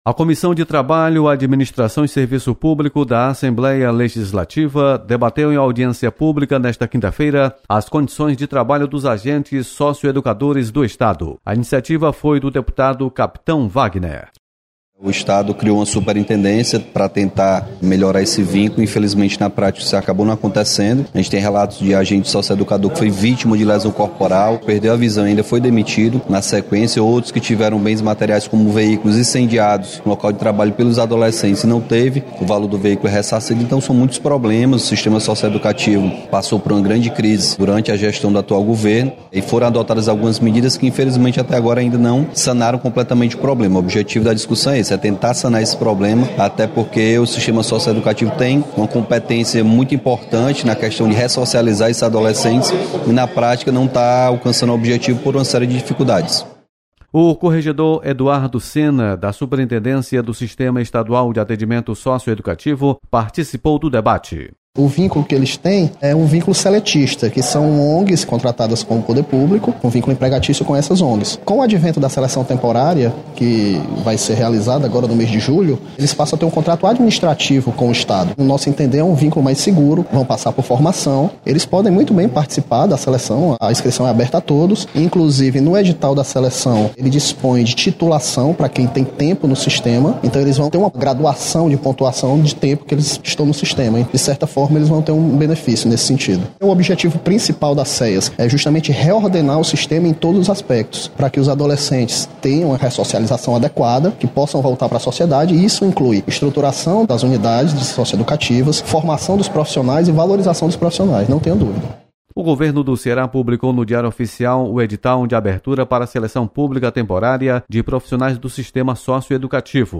Comissão debate condições de trabalho dos agentes socioeducadores. Repórter